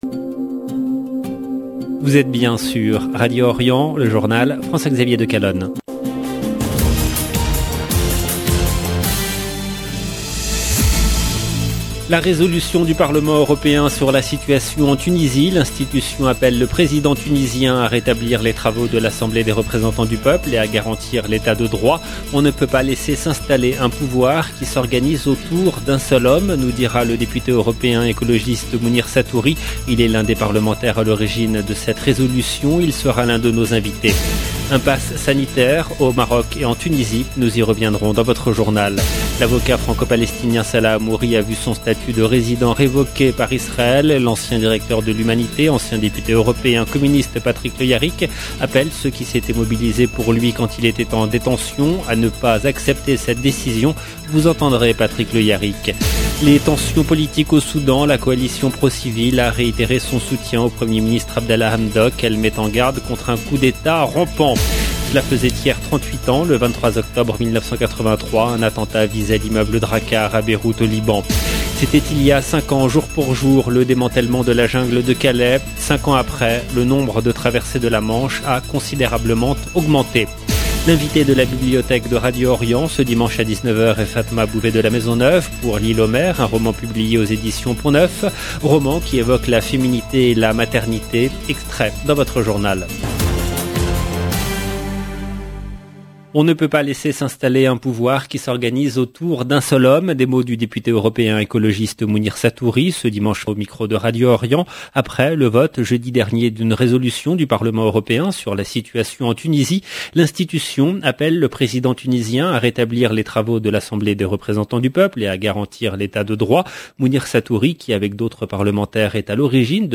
Vous entendrez Patrick Le Hyaric. Les tensions politiques au Soudan.